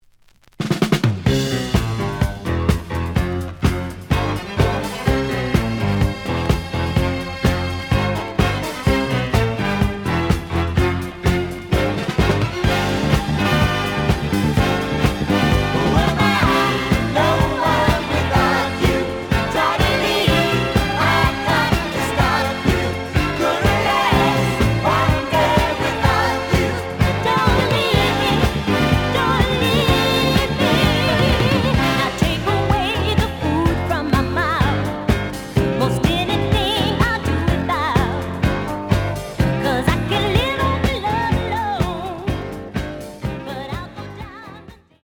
(Short Version) (Mono)
The audio sample is recorded from the actual item.
●Genre: Soul, 70's Soul